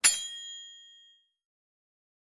menu-direct-click.wav